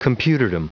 Prononciation du mot computerdom en anglais (fichier audio)
Prononciation du mot : computerdom